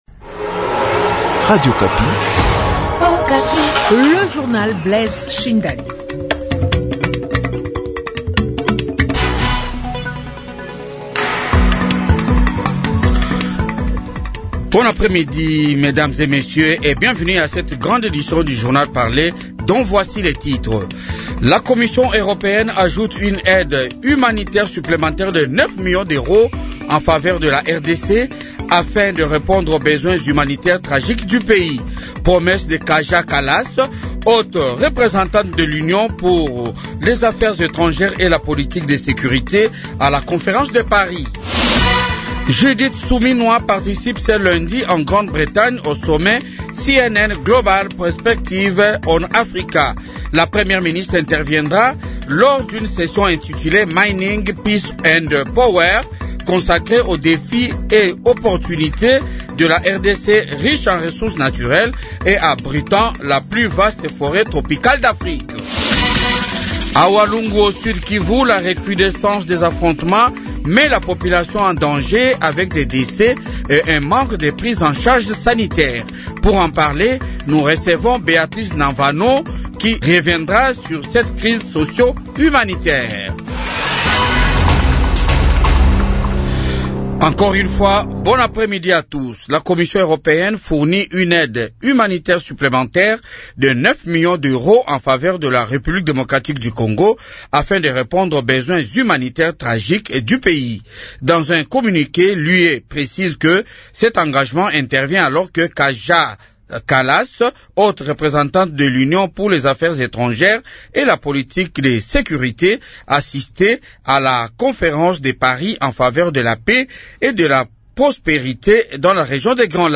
journal de 15h